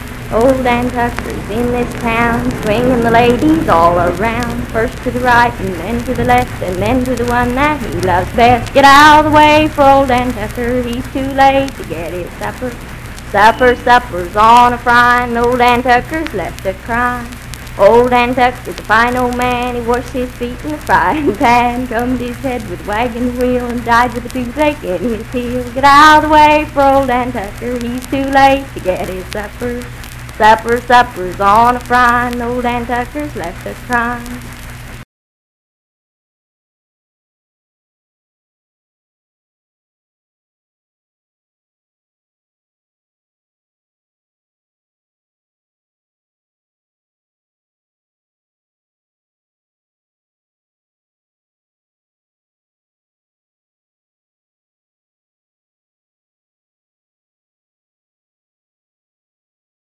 Unaccompanied vocal music
Dance, Game, and Party Songs
Voice (sung)
Wood County (W. Va.), Parkersburg (W. Va.)